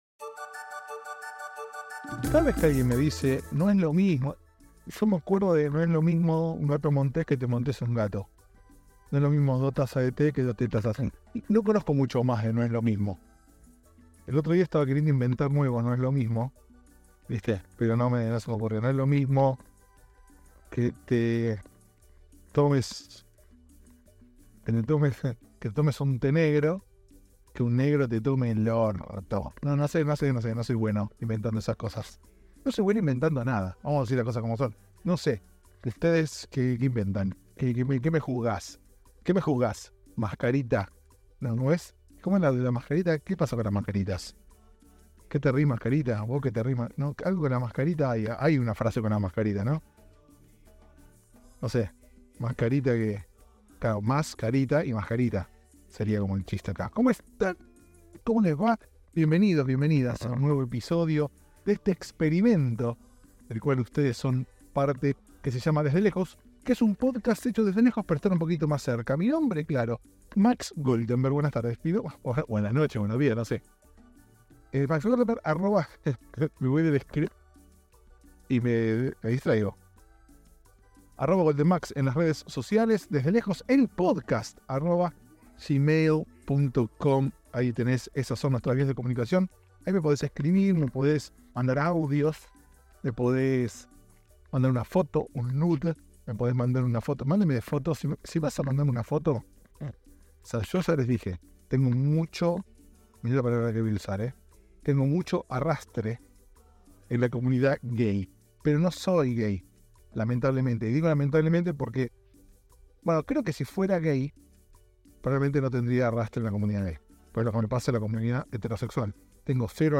El sonido no acompaña pero quedó tan lindo que lo dejé así sucio y todo.